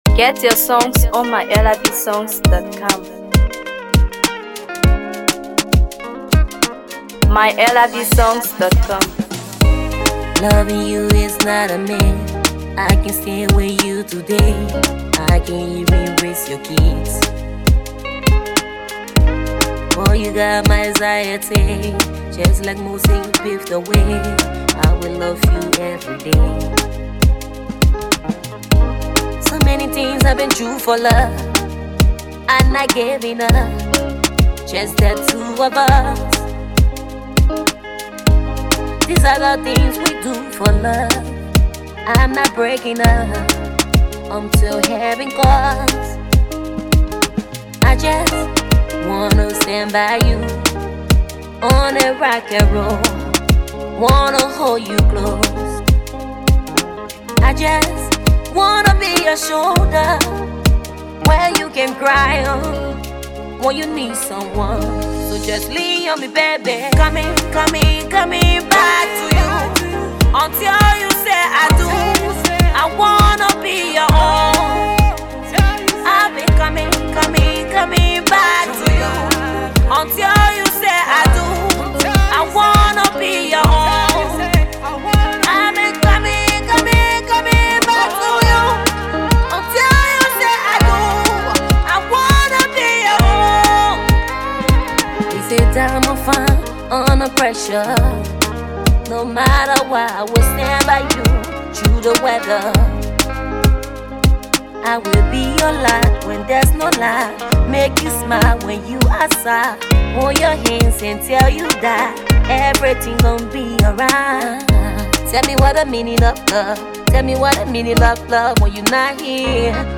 Afro PopMusic
Promising female artist